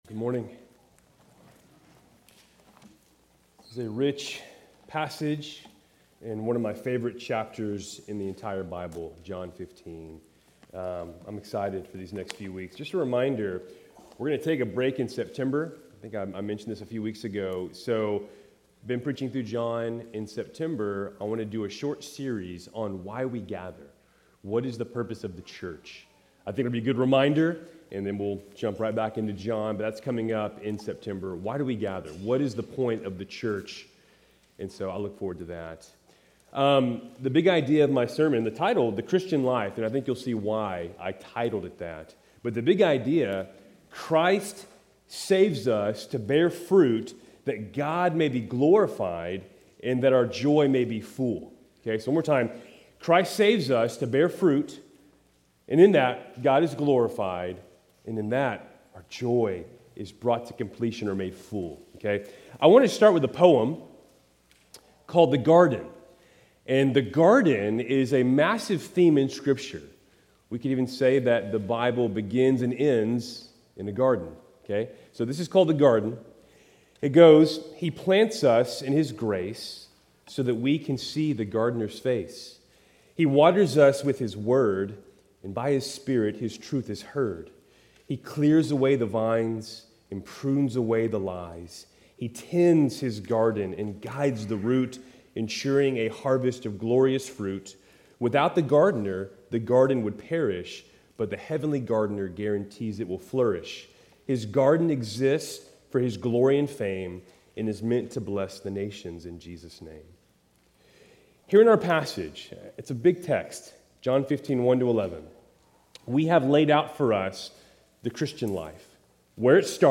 Keltys Worship Service, August 17, 2025